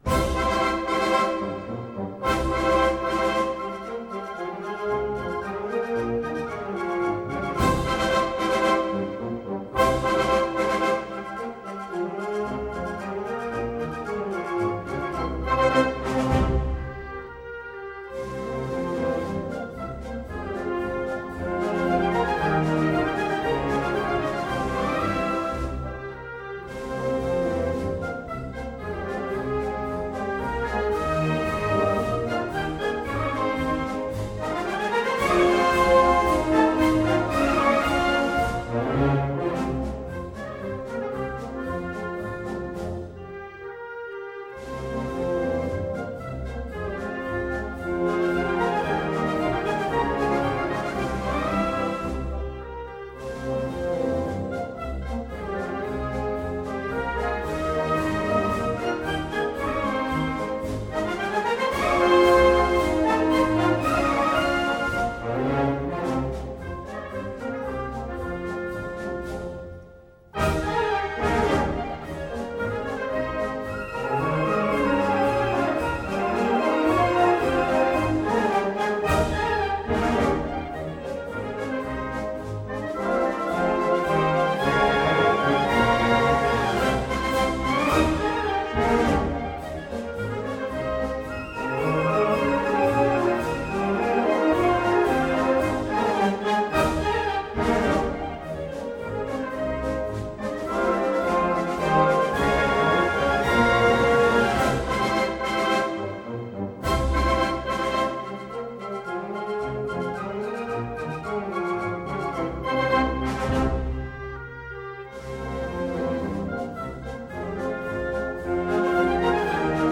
Pasodoble torero
banda / piano